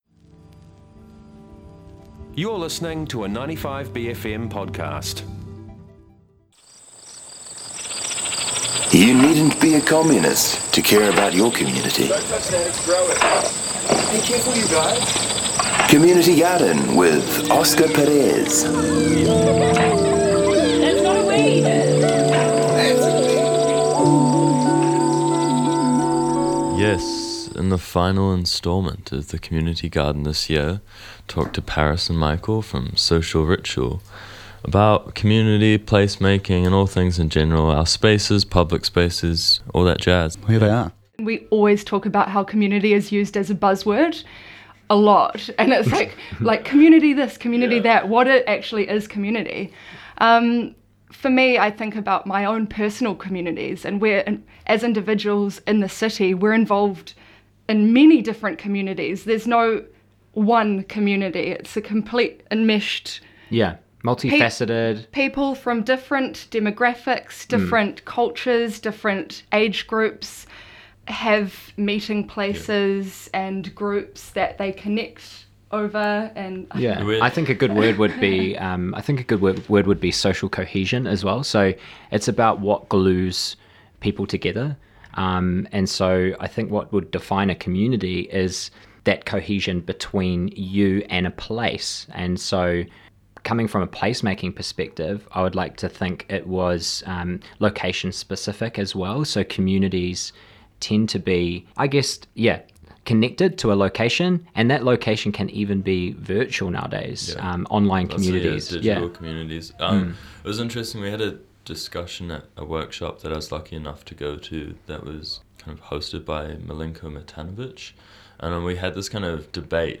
The Community Garden; A Conversation about our Attachment to Space; December 20, 2018
This is the extended cut (Oh, and it doesn't include the 20 minutes of chatting off air!)